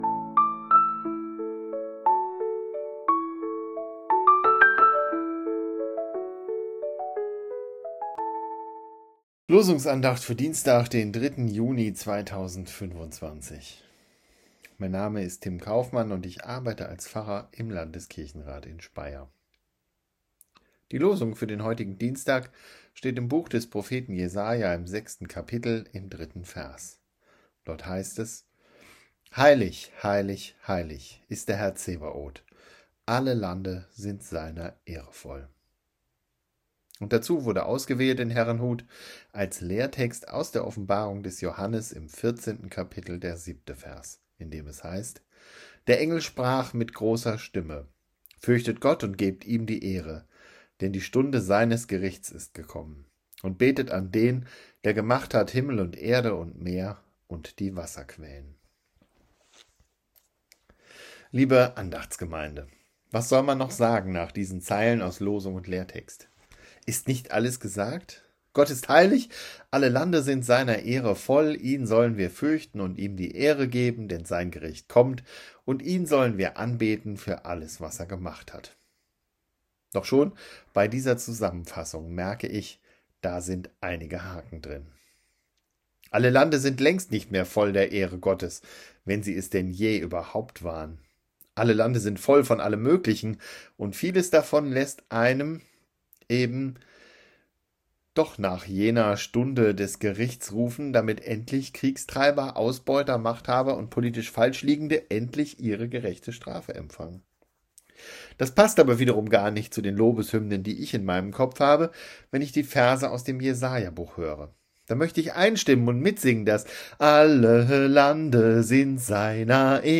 Losungsandacht für Dienstag, 03.06.2025